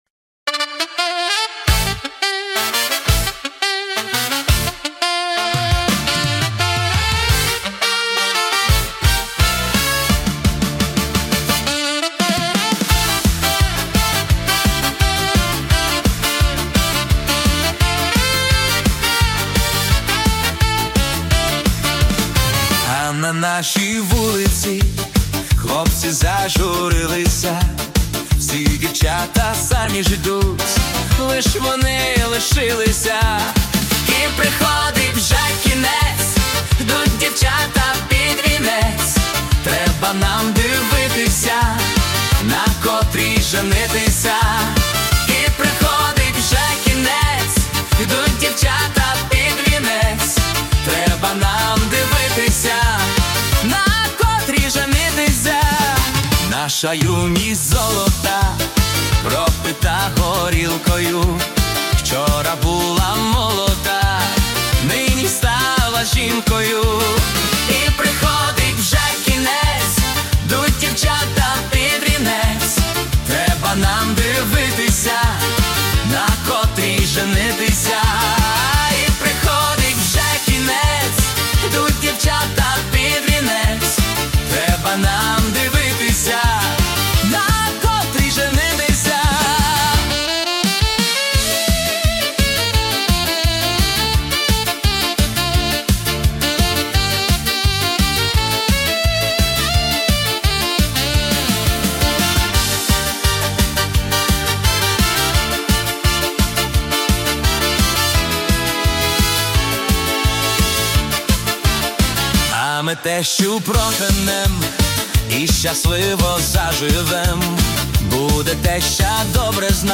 Гарячі та веселі пісні на ваше свято.